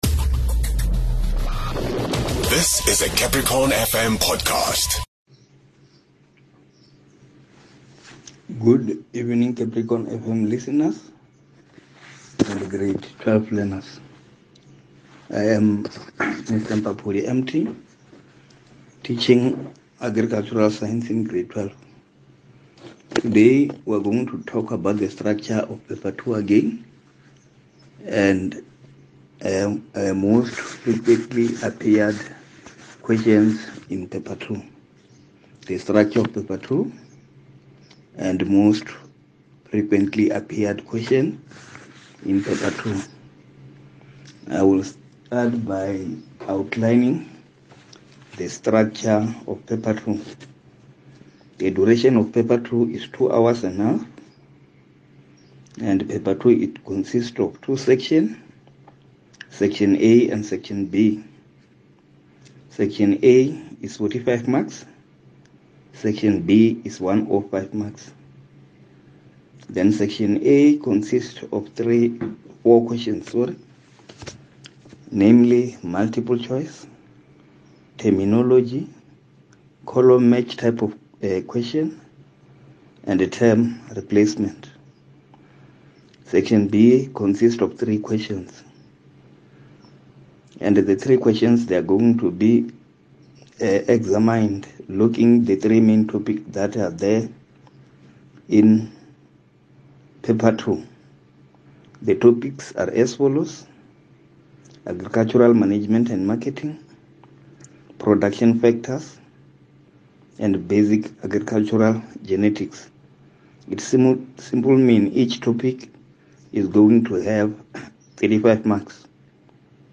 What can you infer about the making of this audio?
As the year edges to an end, the Limpopo Department of Basic Education has dedicated time everyday on CapricornFM to helping Grade12 learners catch up on various lessons.